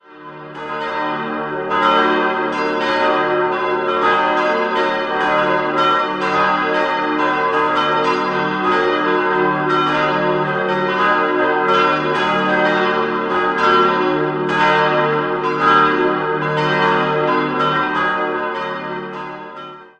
Straubing, Evang. Christuskirche Die in der Straubinger Innenstadt gelegene Christuskirche konnte im Jahr 1957 eingeweiht werden, nachdem ein Vorgängerbau vom Ende des 19. Jahrhunderts einem Bombenangriff im Zweiten Weltkrieg zum Opfer fiel. Blickfang im schlichten Inneren ist der große Wandteppich, auf dem Christus mit einladender Geste die Besucher willkommen heißt. 4-stimmiges Geläut: es'-g'-b'-c'' Die Glocken wurden 1956 von Friedrich Wilhelm Schilling in Heidelberg gegossen.